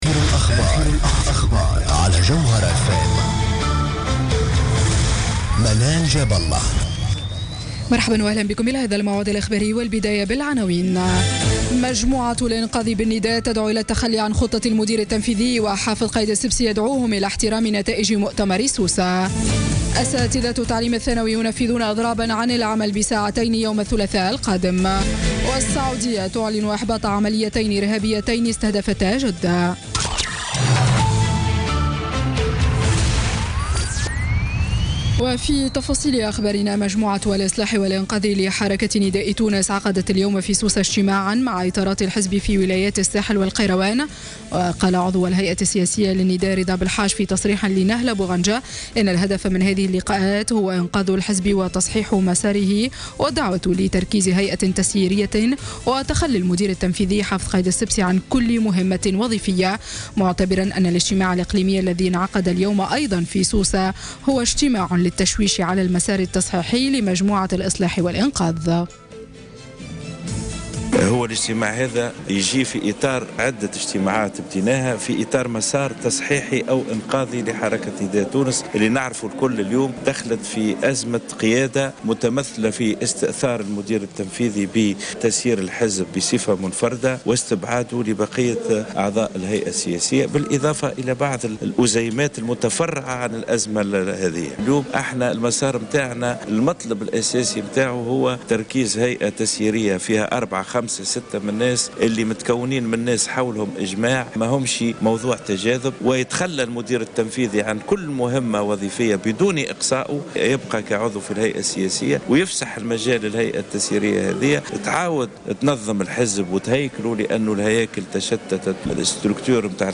نشرة أخبار السابعة مساء ليوم الأحد 30 أكتوبر 2016